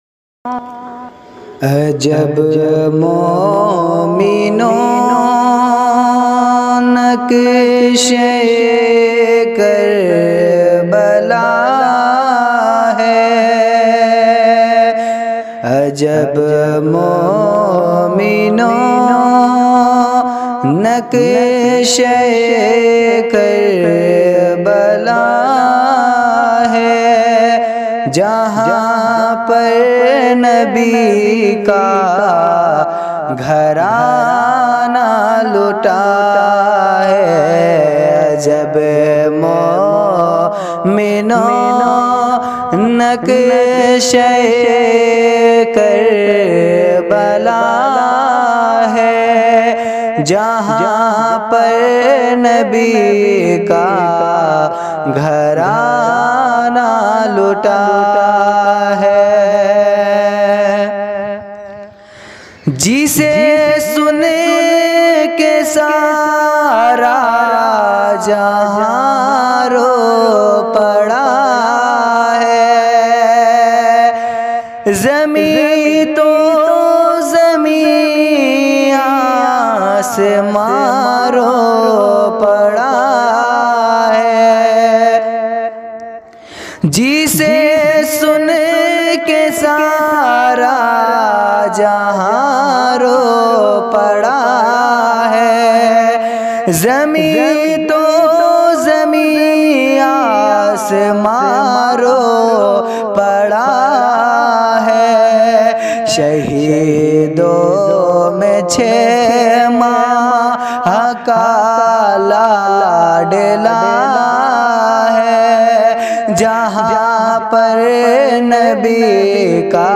Muharram Naat